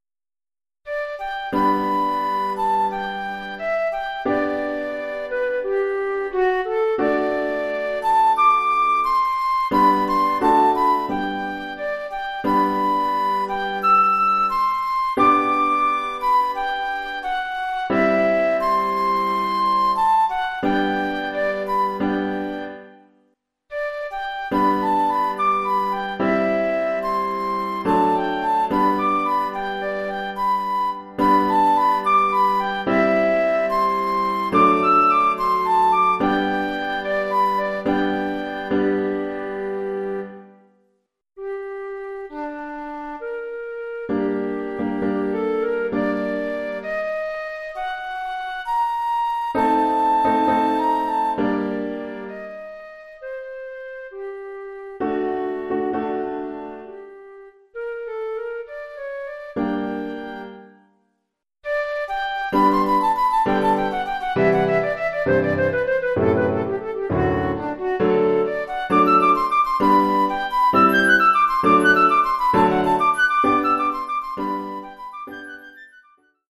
1 titre, flûte et piano : conducteur et partie de flûte
Oeuvre pour flûte et piano.